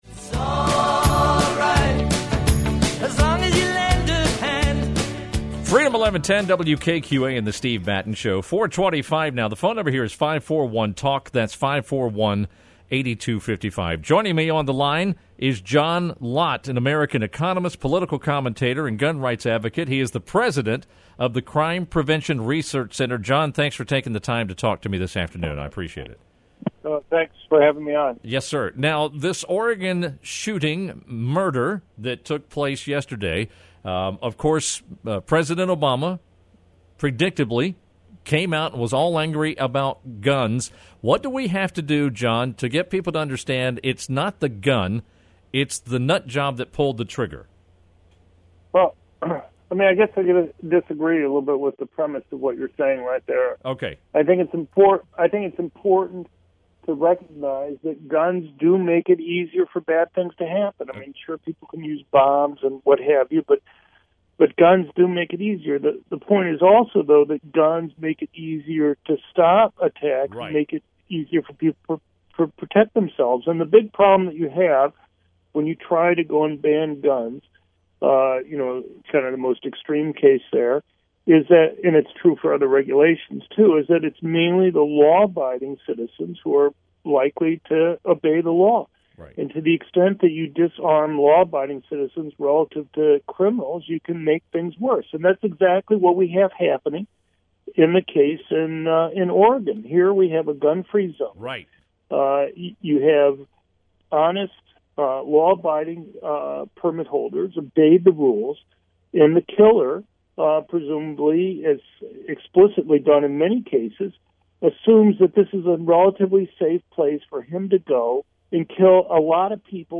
Norfolk, Virginia’s WKQA on Friday, October 2, 2015, over 9 minutes.